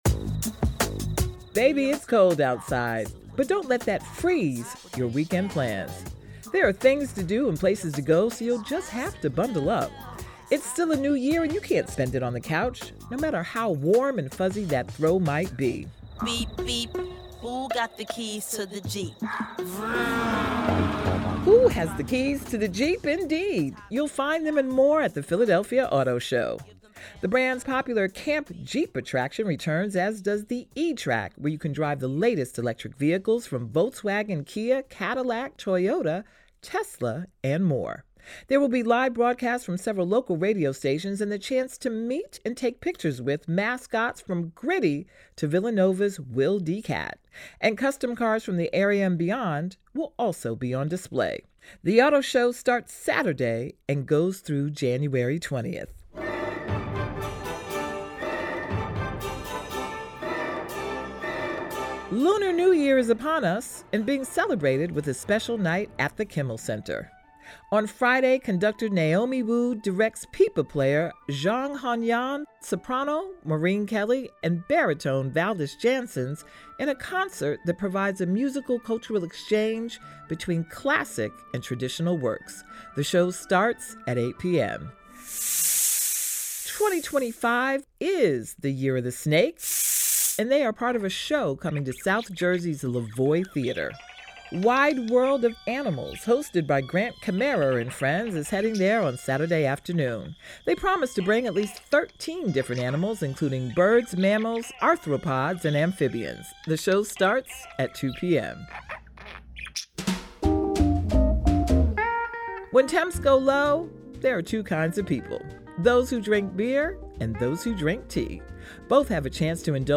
A conversation with Michael Kelly, executive director of the Philadelphia Housing Authority
Brought to you by Radio Times Radio Times WHYY's Radio Times is an engaging and timely call-in program that tackles wide-ranging issues of concern to listeners in the Delaware Valley.